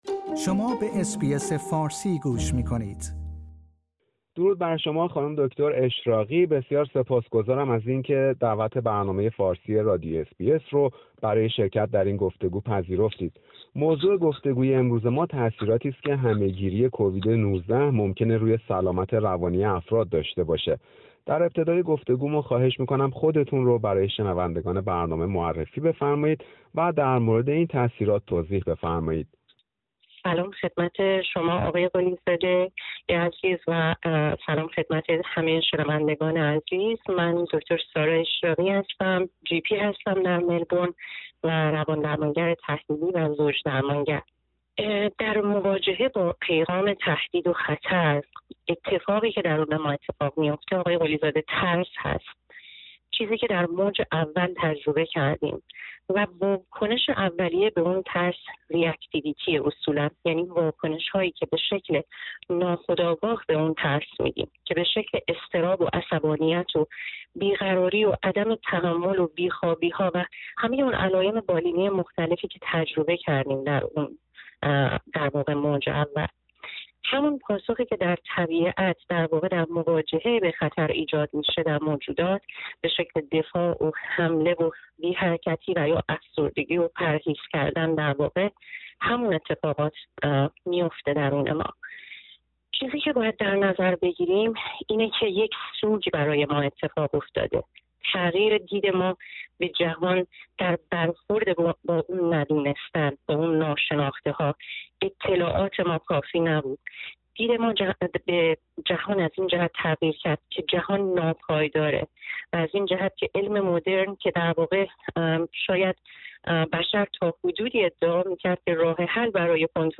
برنامه فارسی رادیو اس بی اس در همین خصوص گفتگویی داشته